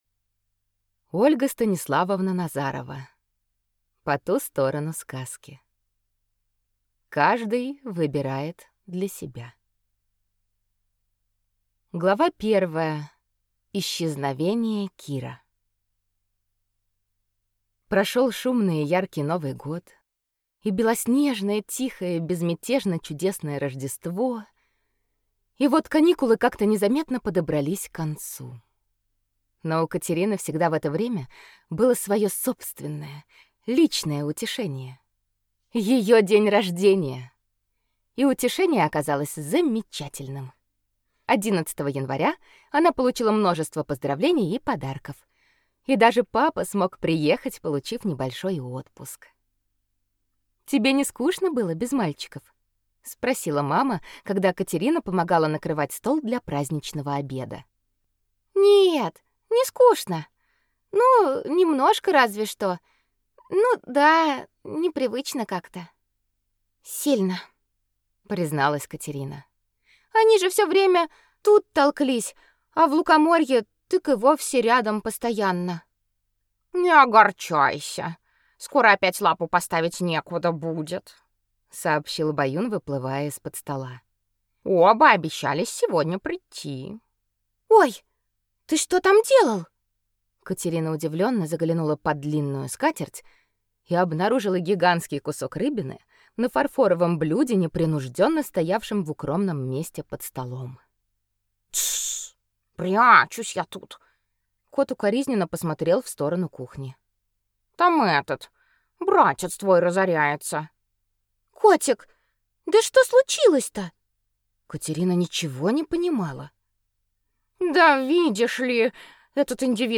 Аудиокнига По ту сторону сказки. Каждый выбирает для себя | Библиотека аудиокниг